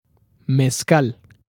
Mezcal (/mɛˈskæl/ , Latin American Spanish: [mesˈkal]
MezcalPronunciation.ogg.mp3